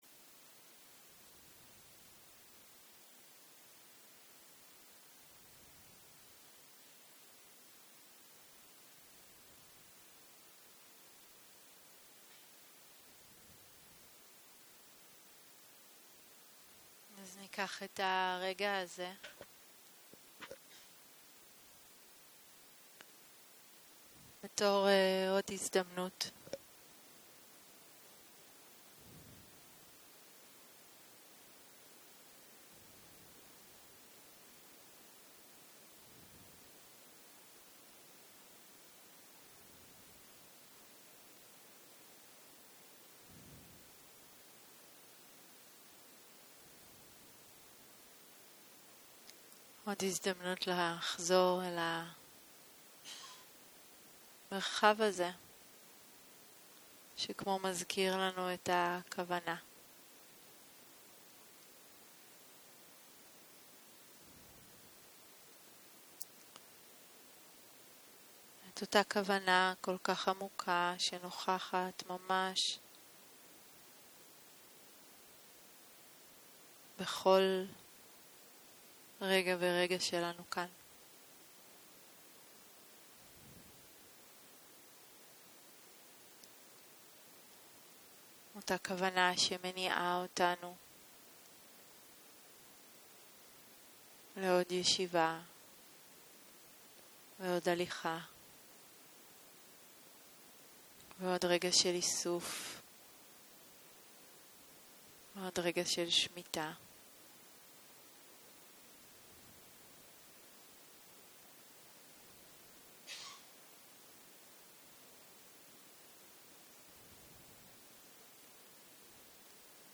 יום 5 - צהרים - מדיטציה מונחית - מודעות רחבה ומצבי תודעה - הקלטה 12